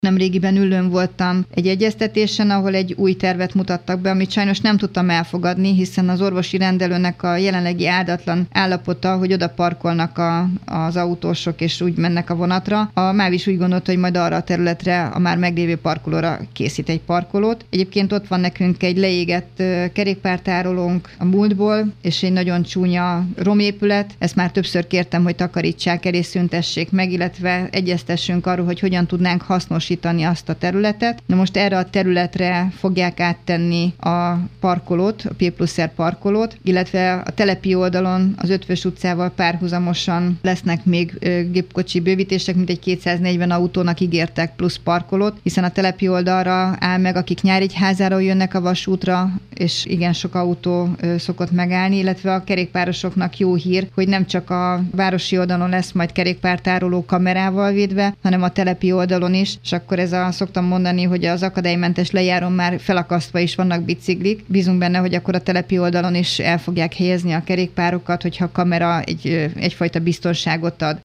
A városi és a telepi oldalon is kamerával megfigyelt kerékpártároló épül, valamint rendeződni látszik egy korábban kihasználatlan terület ügye is, ahol szintén több gépkocsi fér majd el. Hajnal Csilla polgármestert hallják.